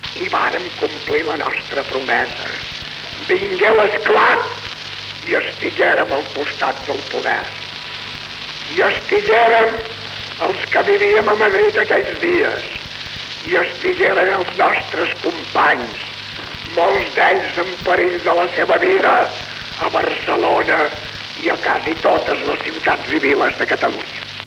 Fragment del discurs radiat de Francesc Cambó amb motiu de l'acte d'inauguració de la Secció escolar de la Joventut Catalanista de Terrassa, celebrat al Teatre Principal.